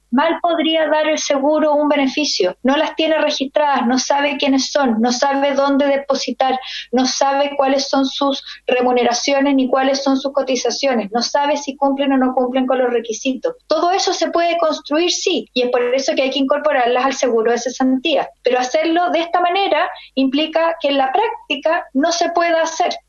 En la misma línea, la ministra del Trabajo, María José Zaldívar, comprometió la presentación de un proyecto que incluya a las trabajadoras de casa particular en el seguro de cesantía, pero se sumó a las críticas respecto de lo propuesto por la Cámara.